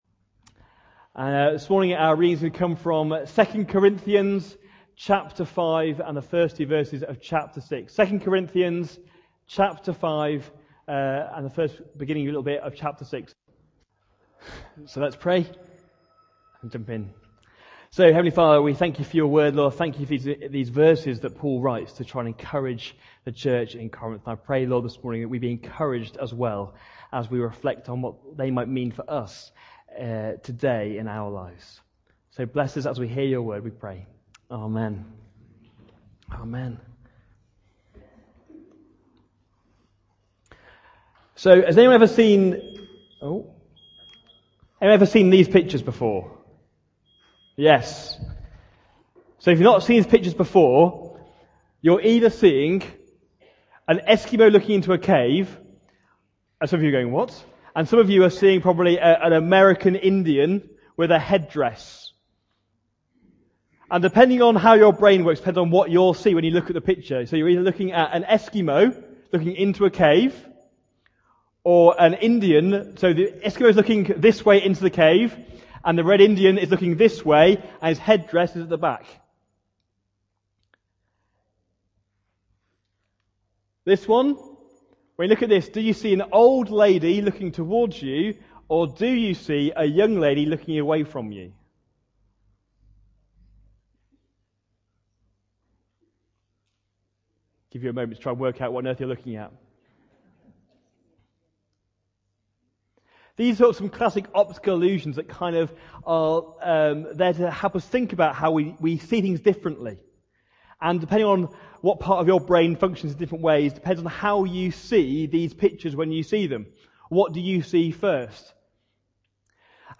Sermons in this Series This message looks at the bigger picture of God's story and how when we change our perspective we become ambassadors of Christ